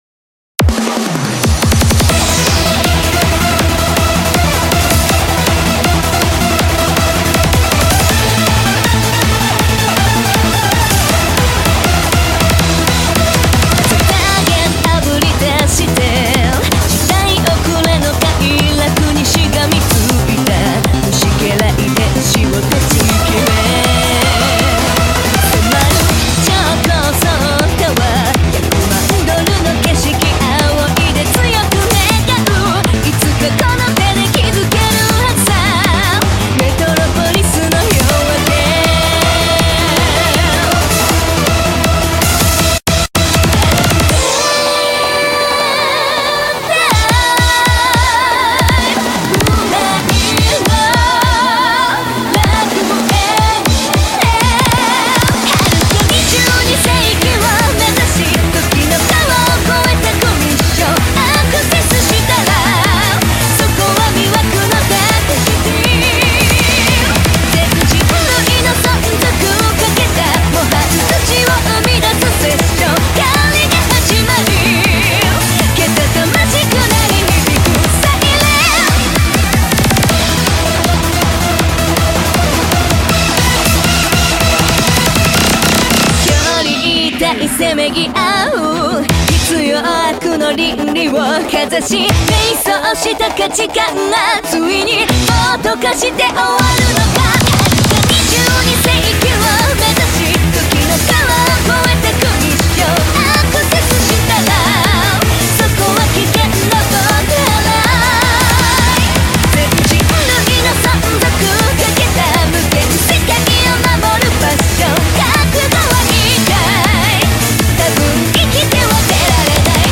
BPM160
Audio QualityPerfect (Low Quality)